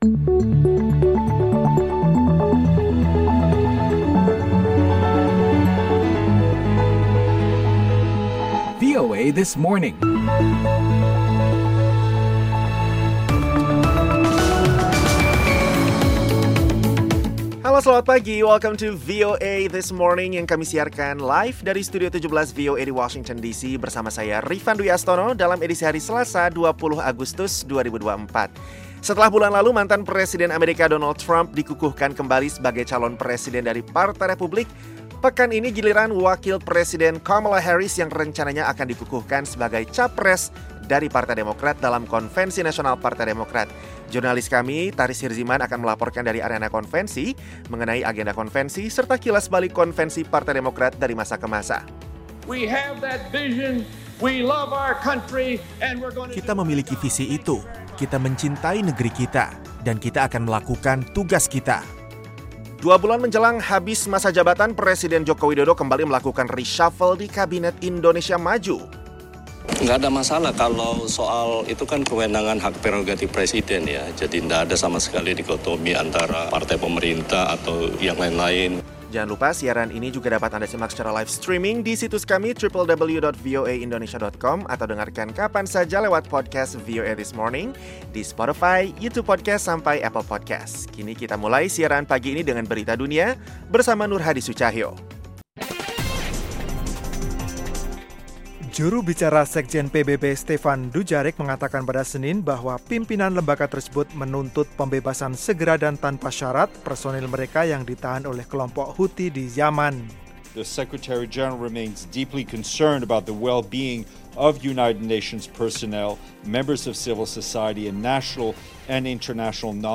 Jurnalis VOA melaporkan langsung dari arena Konvensi Nasional Partai Demokrat di Chicago mengenai agenda konvensi, termasuk pidato Wapres AS Kamala Harris yang akan menerima secara resmi pencapresannya. Sementara itu, Presiden Jokowi reshuffle kabinetnya menjelang lengser.